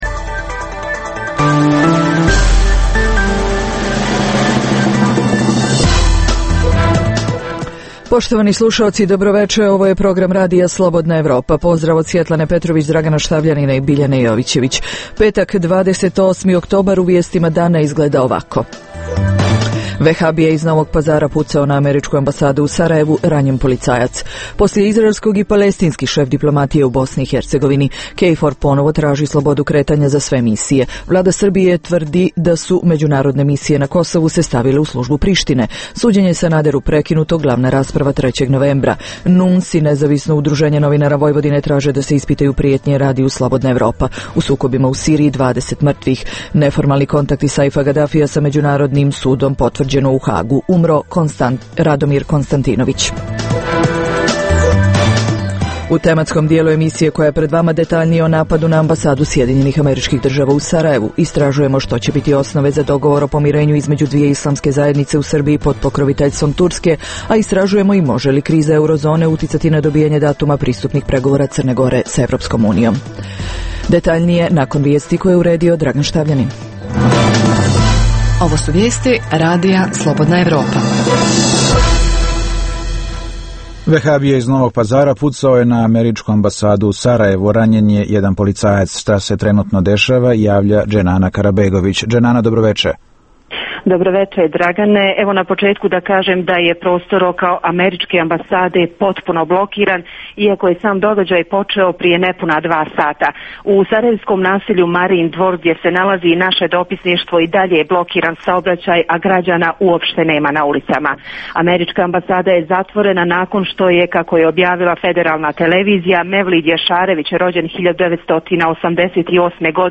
- Za RSE govori član Predsjedništva BIH Bakir Izetbegović. - Istražujemo i može li kriza eurozone uticati na dobijanje datuma pristupnih pregovora CG sa EU?